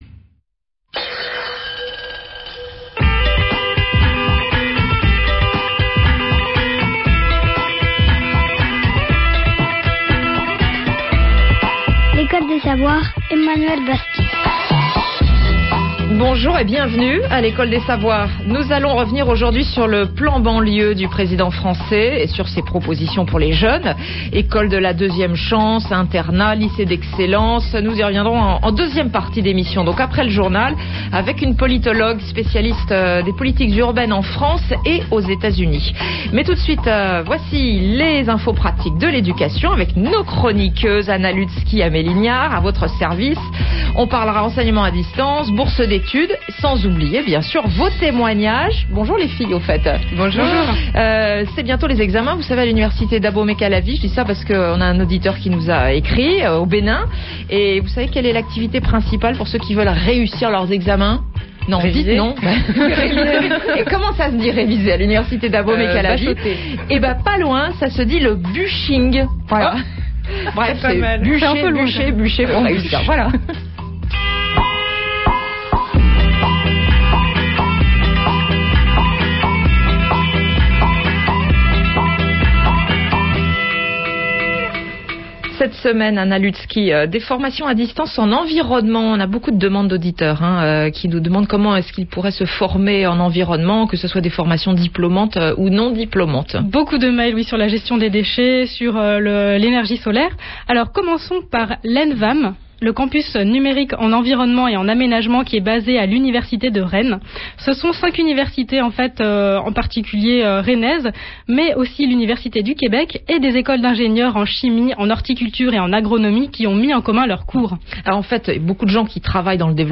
La radio RFI a diffusé le samedi 16 février 2008 une émission de radio sur les formations en ligne dans le domaine de l'environnement. Il a été question des formations proposées sur le Portail de l'environnement.